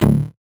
CS_VocoBitB_Hit-15.wav